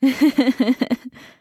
female_chuckle2.ogg